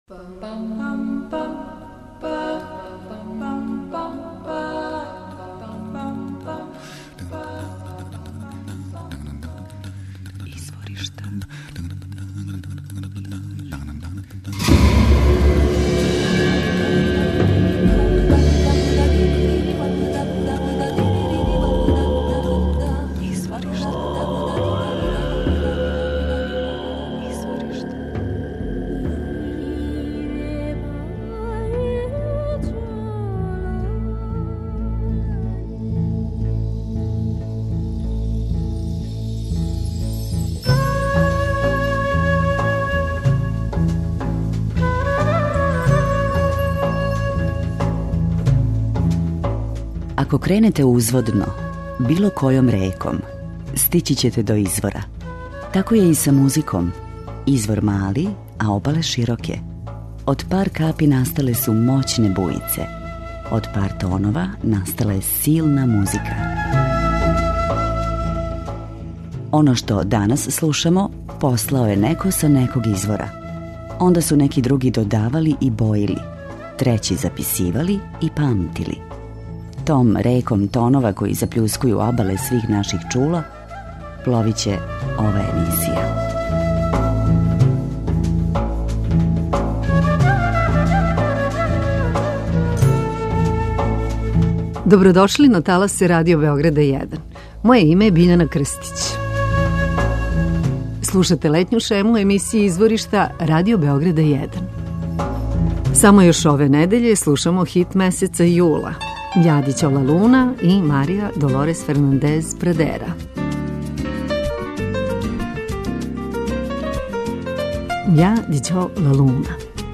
Дружићемо се са музичарима чију музику дефинишу у оквирима world music.